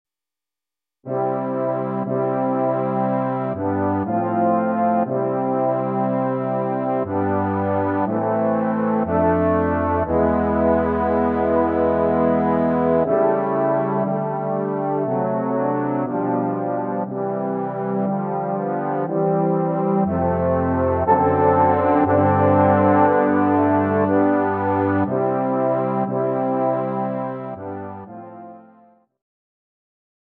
【編成】トロンボーン四重奏（3 Tenor Trombone and Bass Trombone）